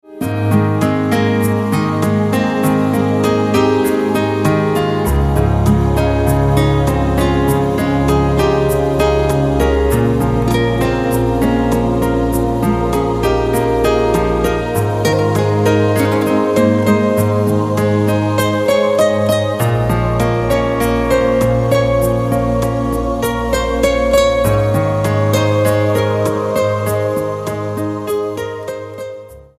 STYLE: Ambient/Meditational
Although instrumental music seldom appeals to me
create a wonderful atmosphere of peaceful tranquillity